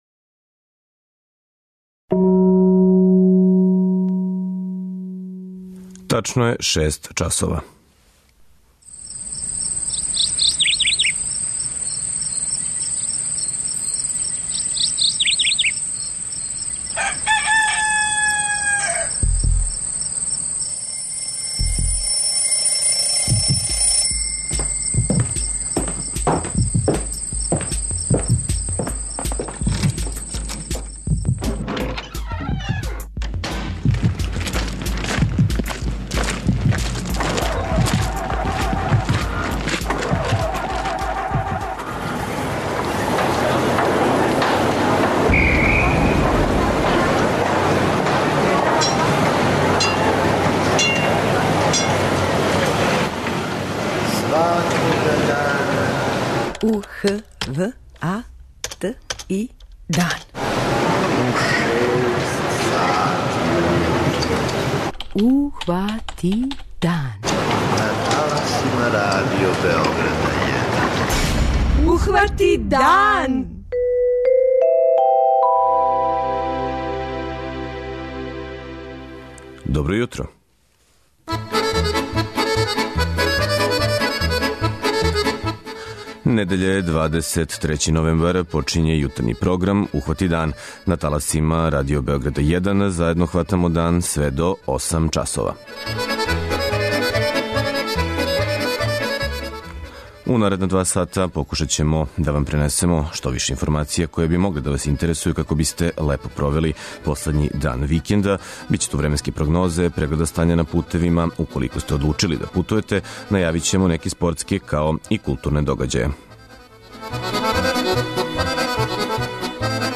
У редовној недељној рубрици "Књига солидарности" говори песник Рајко Петров Ного.